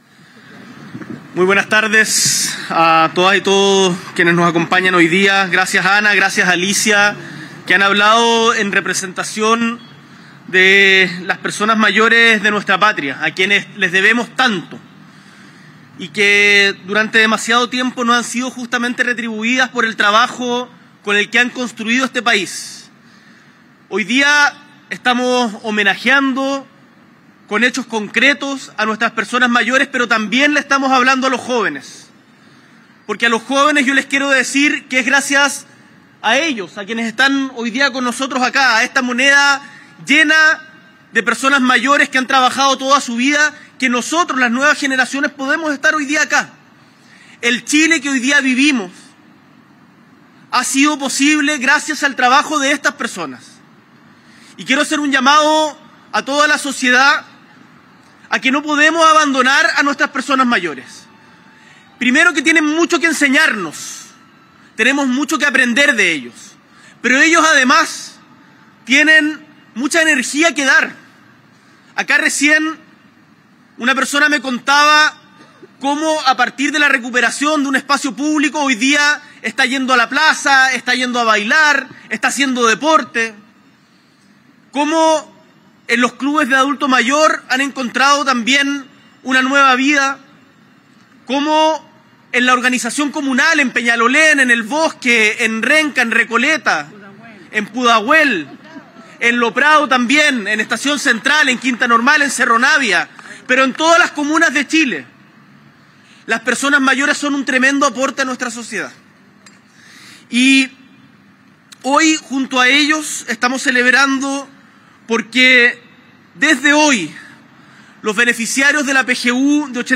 S.E. el Presidente de la República, Gabriel Boric Font, encabeza encuentro con personas mayores por el aumento del pago de la PGU a $250.000 para pensionados de 82 años o más